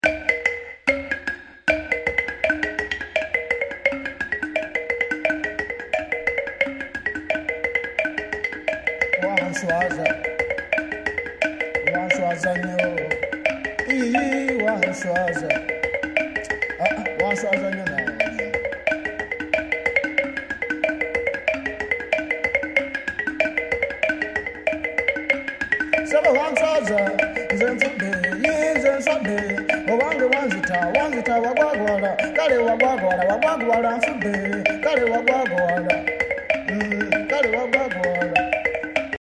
female voice, yodelling, ullulation, handclapping (engalo)